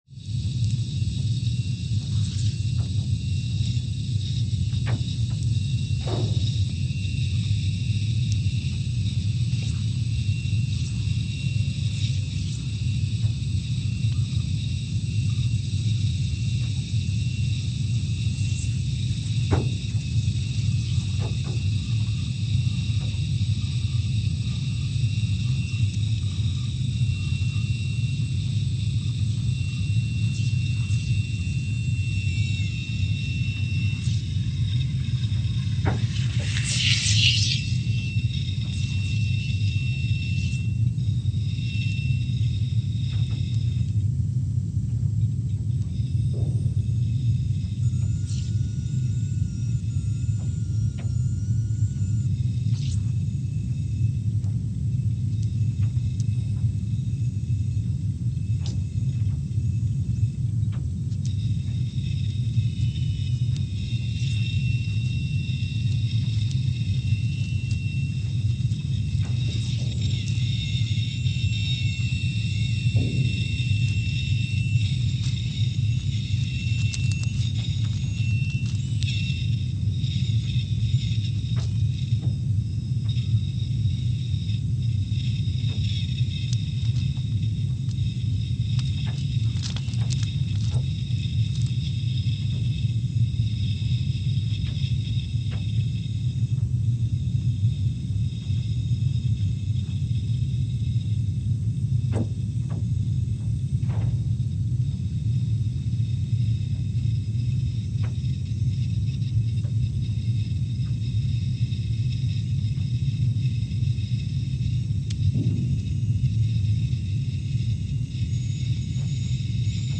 Scott Base, Antarctica (seismic) archived on July 17, 2021
Station : SBA (network: IRIS/USGS) at Scott Base, Antarctica
Sensor : CMG3-T
Speedup : ×500 (transposed up about 9 octaves)
SoX post-processing : highpass -2 90 highpass -2 90